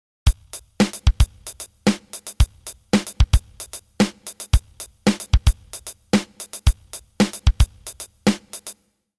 Here’s a rhythmic treat for your dominant hand.
To compensate for the added complexity of the ride hand, which has your addled synapses thinking “No, it’s e+ damnit!”, the snare and kick patterns have been streamlined, cut back to the minimum necessary inputs that will still make the soccer-moms at the PTA gig shake their upper-middle-class booties.
If your band has a percussionist, he’ll thank you for this beat — there’s a lot of room to solo within the pulse. 1 + 2 + 3 + 4 + 4 HH x x x x xx xx 1/4 = 112 - SD o o 4 KD o oo